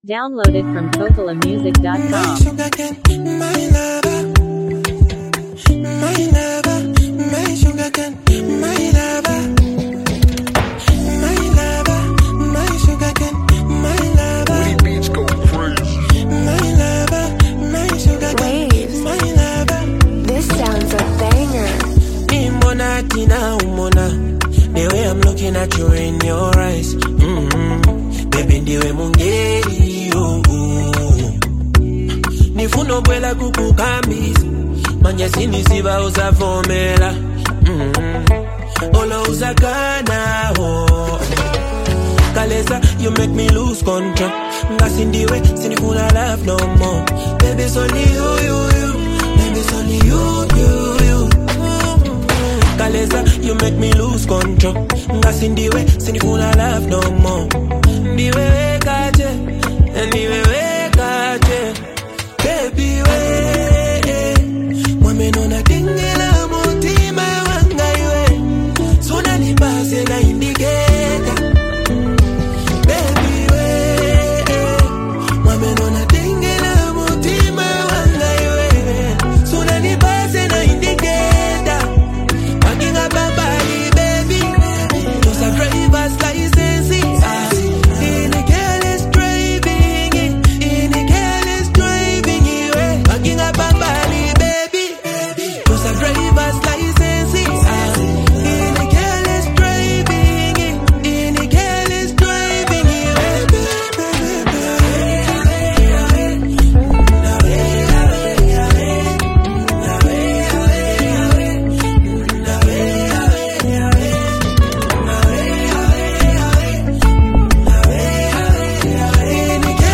is a smooth and emotionally charged song
heartfelt vocals